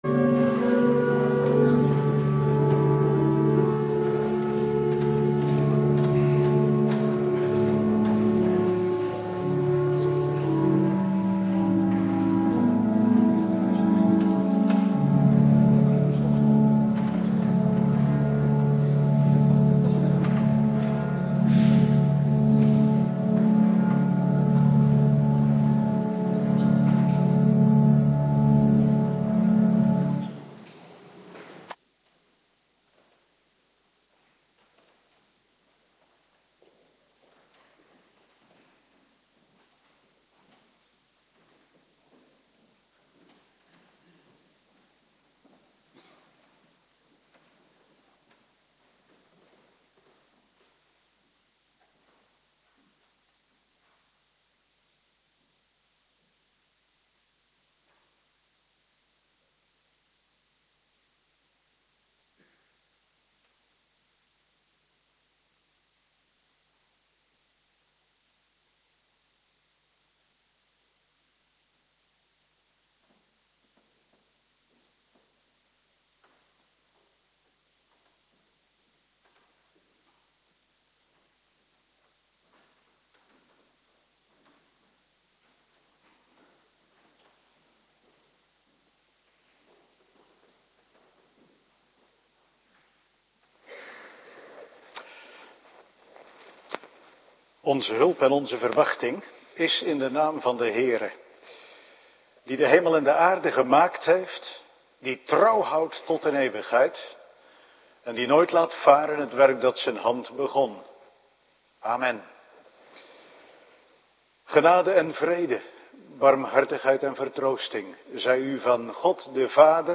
Woensdagavond Bijbellezing